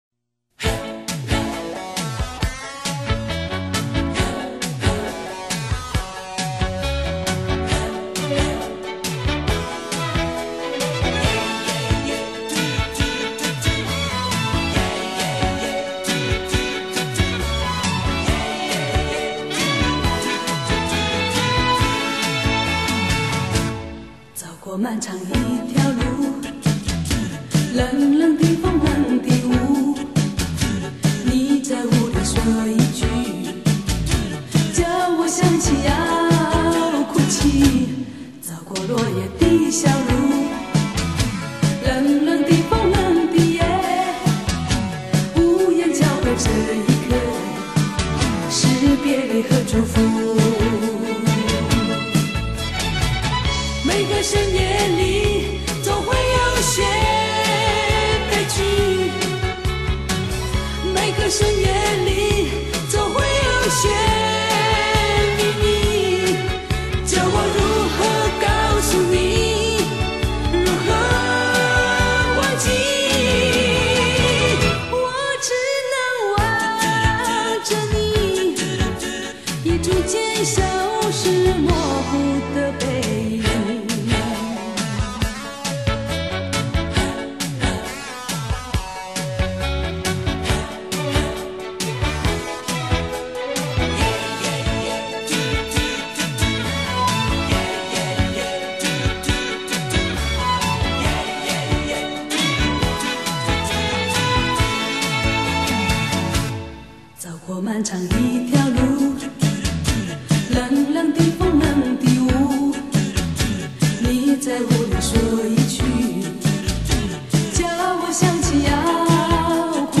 独特的嗓音 别致的唱腔